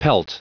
Prononciation du mot pelt en anglais (fichier audio)
Prononciation du mot : pelt